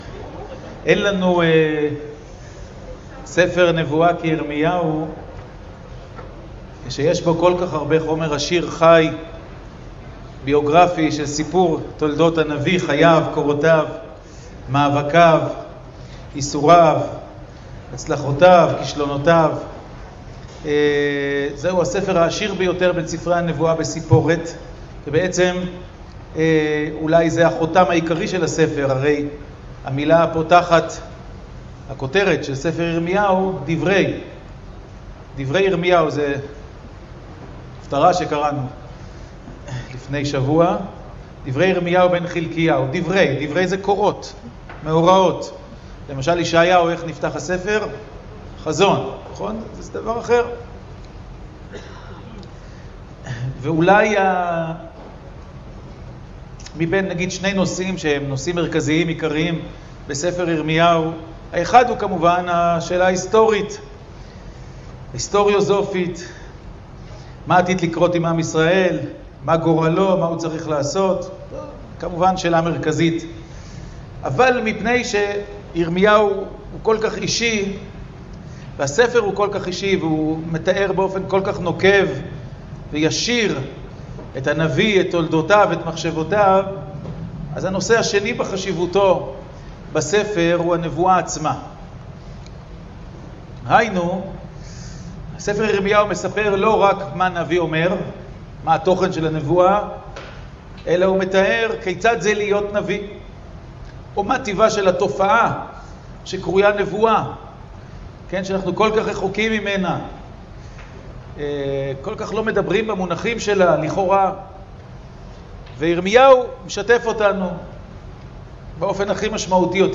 השיעור באדיבות אתר התנ"ך וניתן במסגרת ימי העיון בתנ"ך של המכללה האקדמית הרצוג תשע"ח